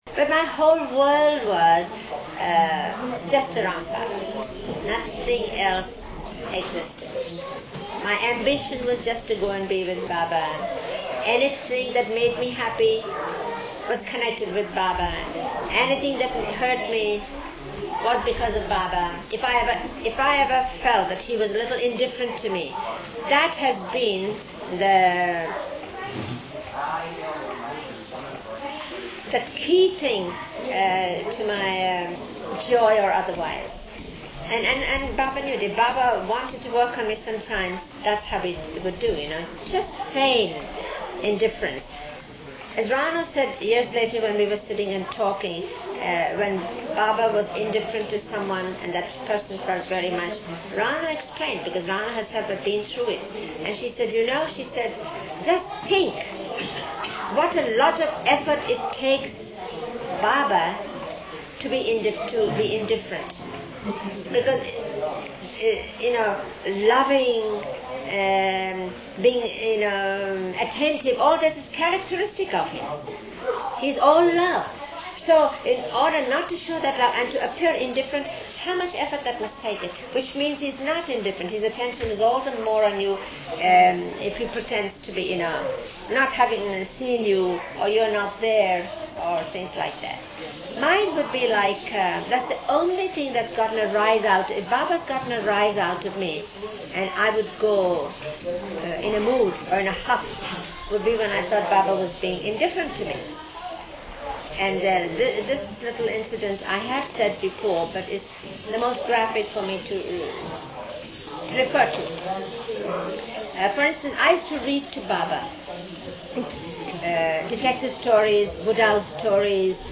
A collection of talks, stories, discussions and musical performances by Avatar Meher Baba's mandali and lovers, recorded primarily in Mandali Hall, Meherazad, India.